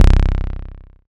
Bass (23).wav